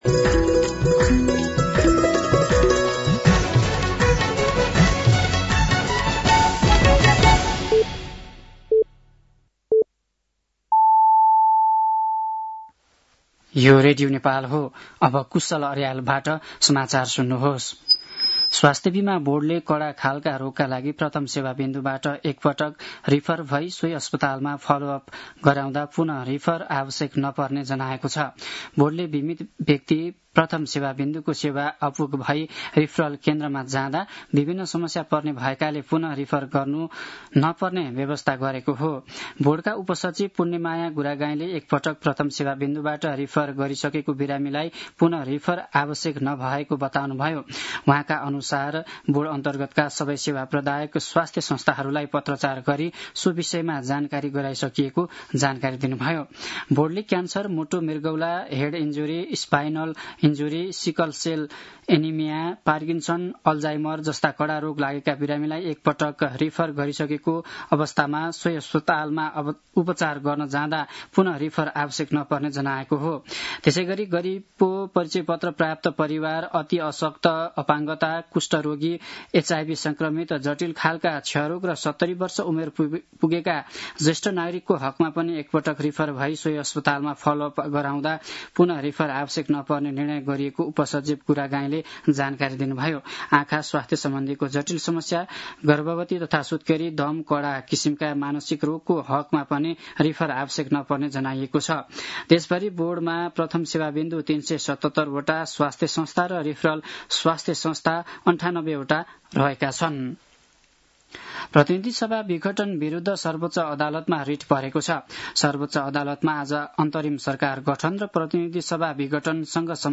साँझ ५ बजेको नेपाली समाचार : २८ असोज , २०८२